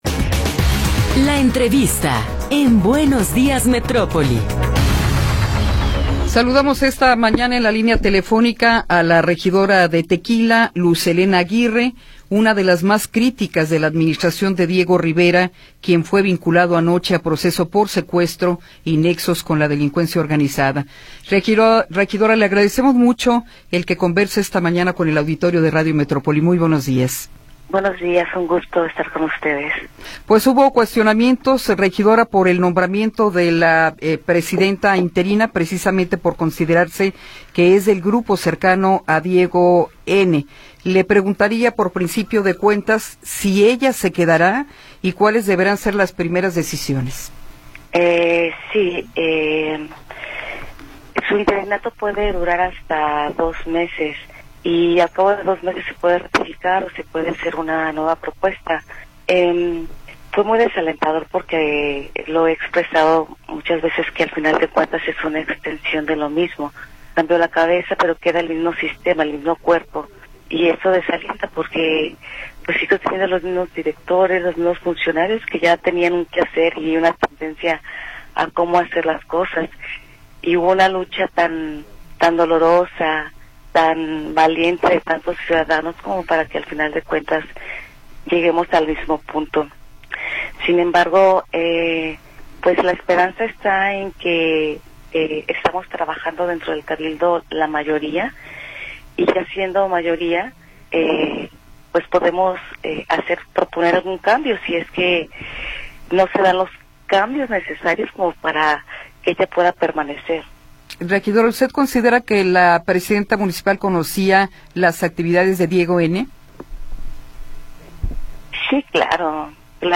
Entrevista con Luz Elena Aguirre